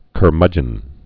(kər-mŭjən)